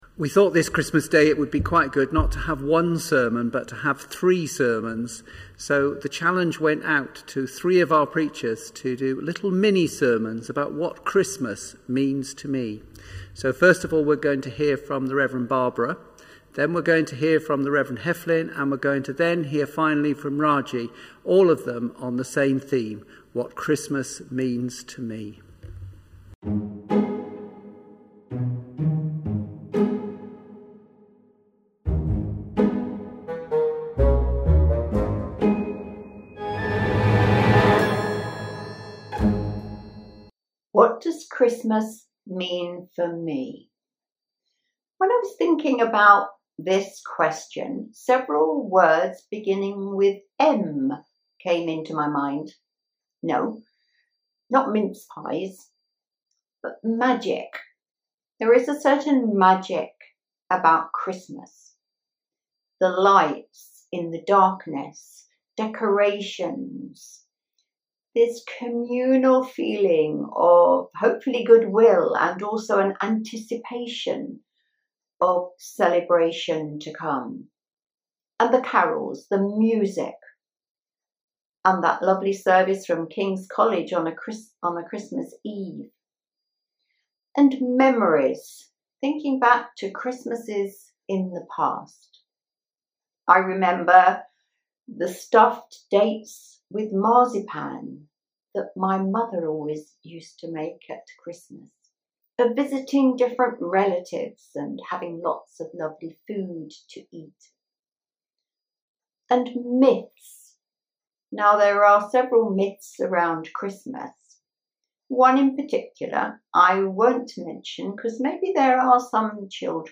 latestsermon-2.mp3